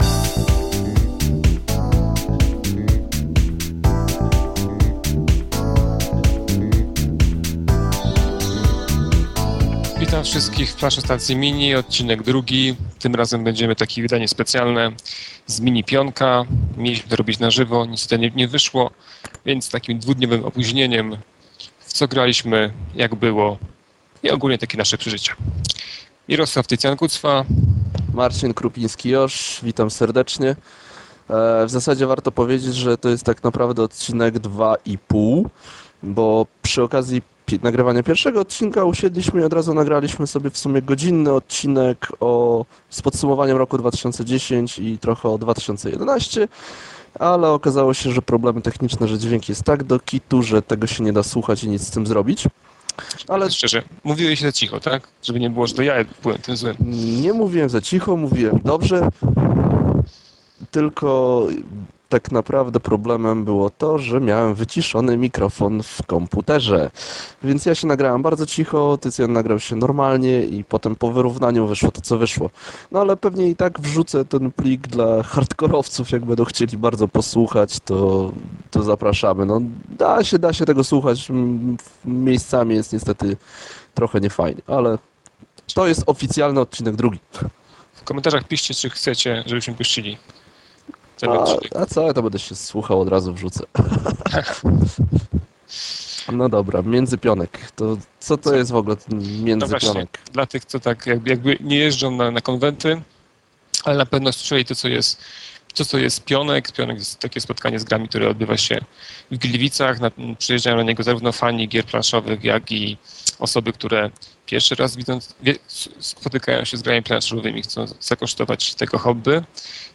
Tym razem dźwięk jest dobrej jakości, ale dla osób o dobrym (albo i wręcz odwrotnie) słuchu mamy mały bonus.